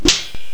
warrior_attack9.wav